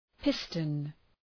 Προφορά
{‘pıstən}